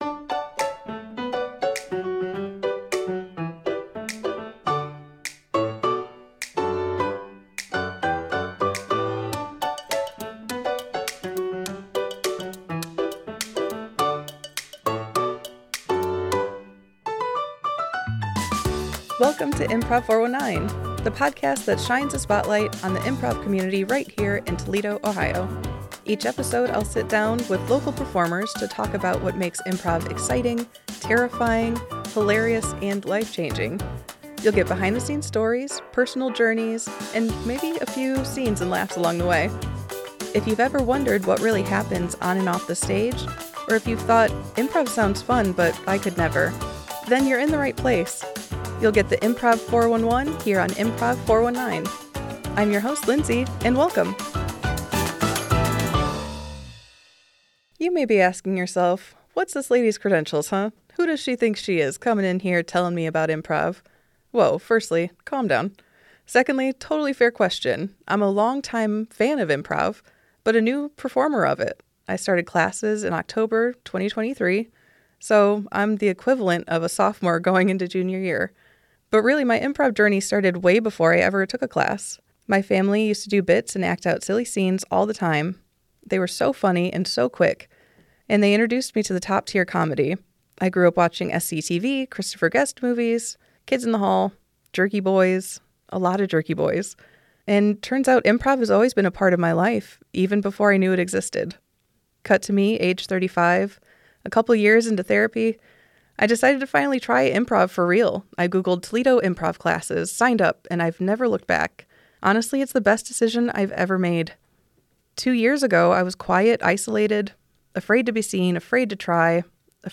We cover her journey from Level 1 student to Level 1 teacher, discuss how improv builds community, and what it means to “ignore the audience” when performing. It’s a conversation about fear, connection, and the joy of getting silly with friends.